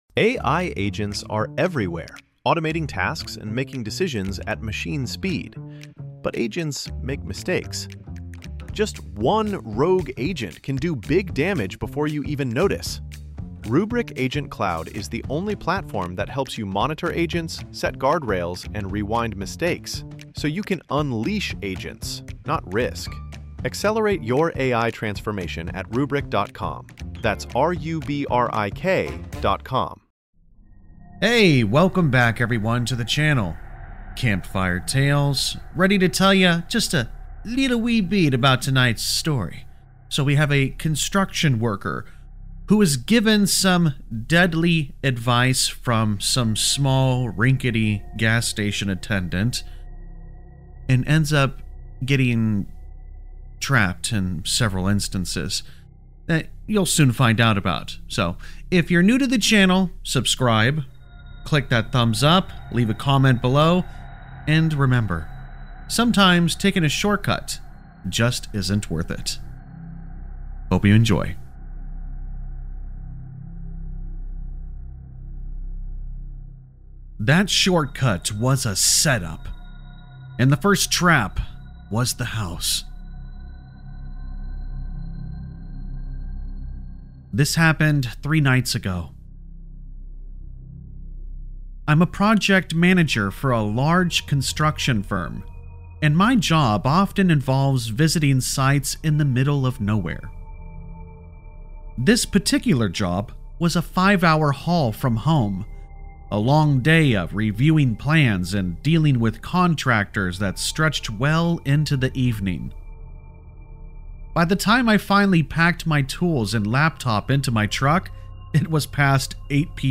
In this chilling Creepypasta horror story, a construction trucker follows a shortcut suggested by a gas station attendant—only to find himself driving straight into a terrifying trap hidden deep within the woods.
All Stories are read with full permission from the authors: